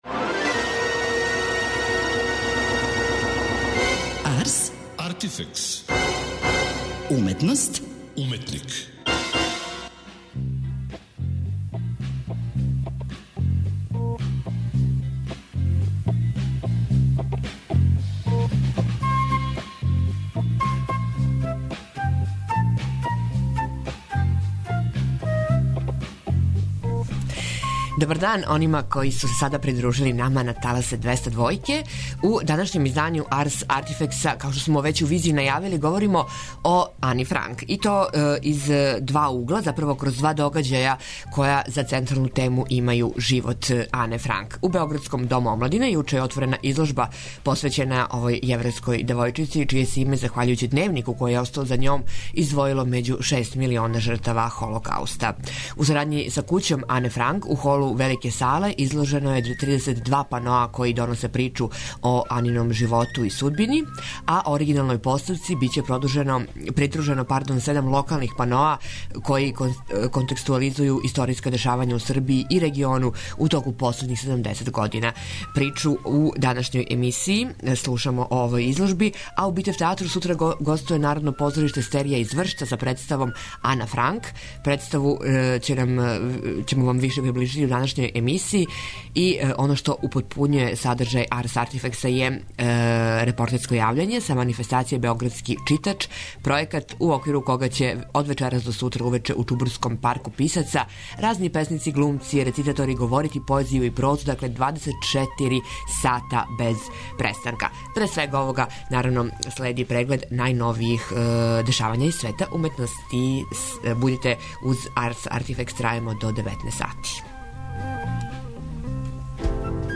Са ''београдског читача'', пројекта у оквиру којег ће од вечерас до сутра увече у Чубурском парку писци, песници, глумци и рецитатори говорити поезију и прозу, јавиће се наш/а репортер/ка, доносећи атмосферу са лица места.